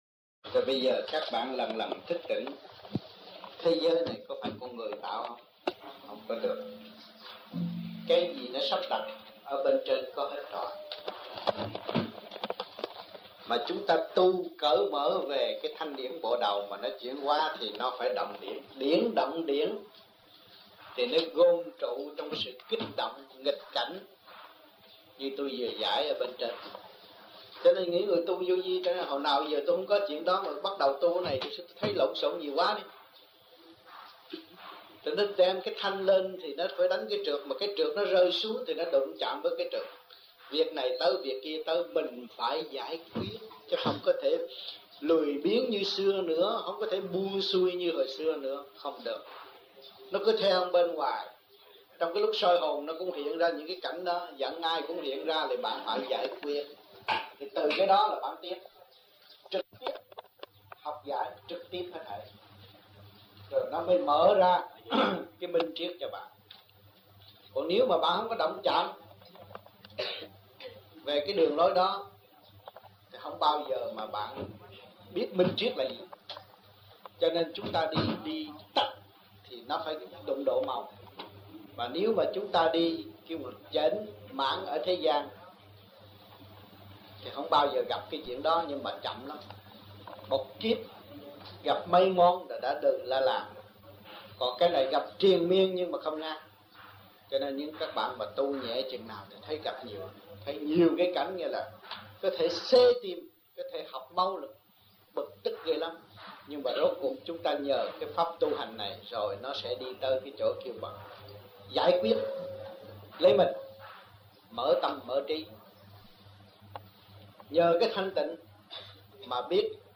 1975-03-30 - SÀI GÒN - THUYẾT PHÁP 1